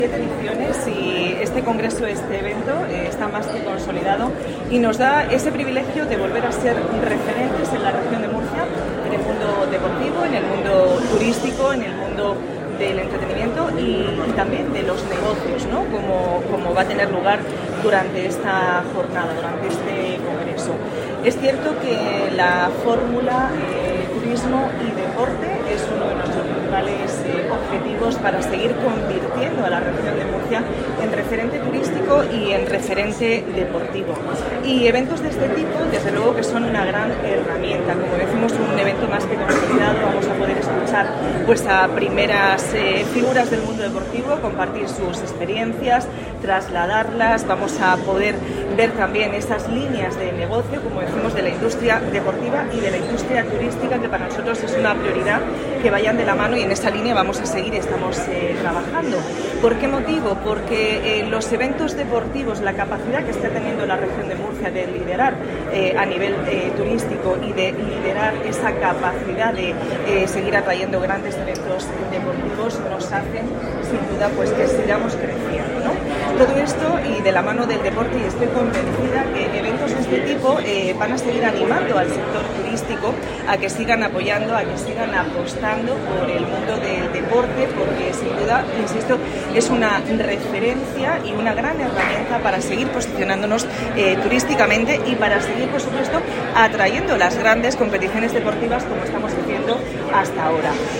Declaraciones de la consejera Carmen Conesa sobre la séptima edición del Costa Cálida Región de Murcia Sport Business